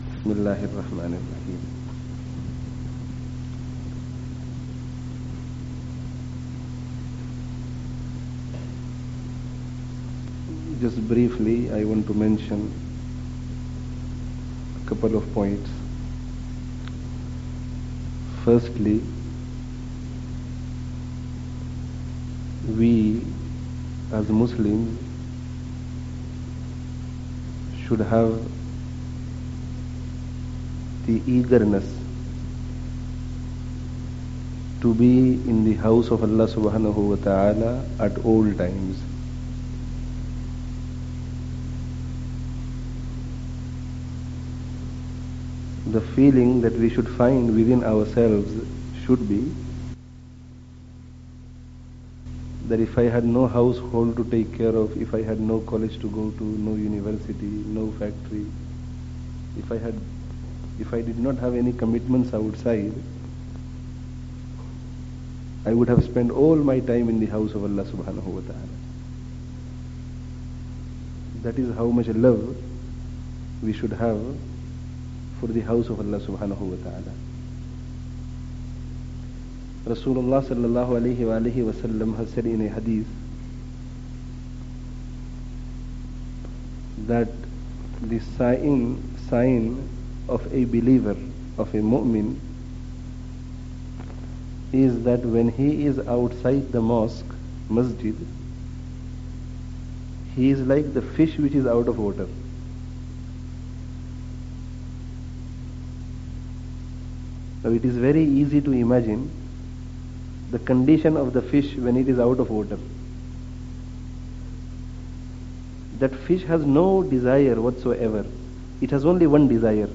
Eagerness for the Masjid (3rd Ramadhān 1420) (Masjid An Noor, Leicester 11/12/99)